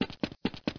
Footstep.mp3